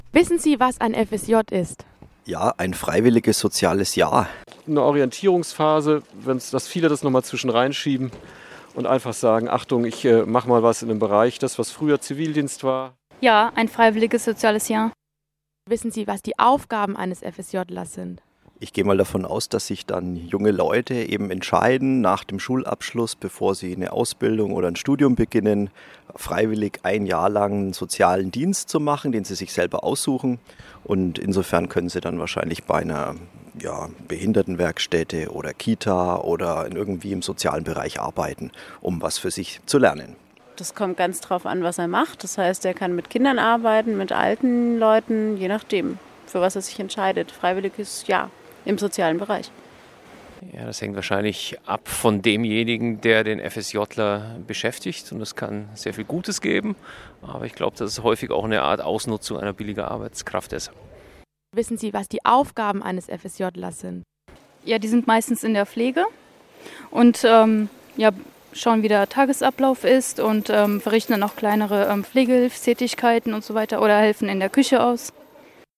Dieser Eintrag wurde veröffentlicht unter Umfrage Gesellschaft & Soziales PH 88,4 Schule & Co. und verschlagwortet mit Freiwilliges Soziales Jahr FSJ Gruppe deutsch am von
Doch wie gut ist das Wissen über das so genannte FSJ unter Passanten in Freiburg? Einige FSJlerinnen und FSJler, Teilnehmer an einem Radioseminar an der PH Freiburg, haben sich umgehört.